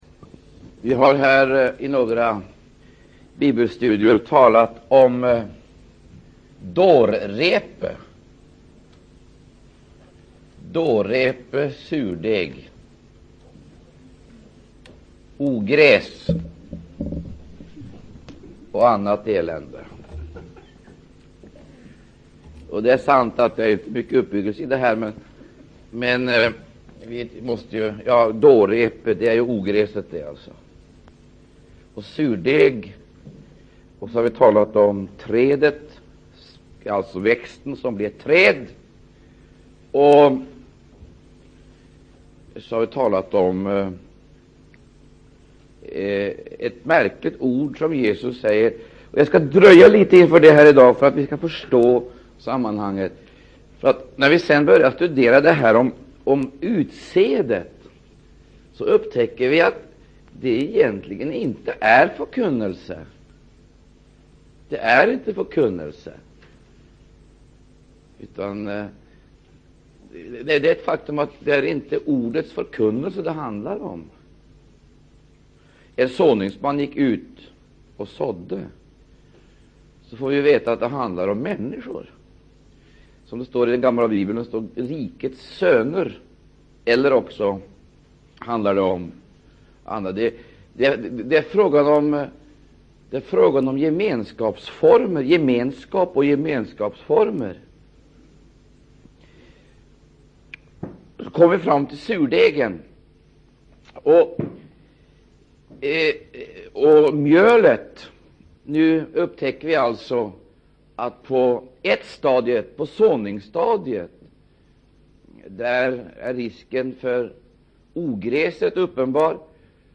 Undervisning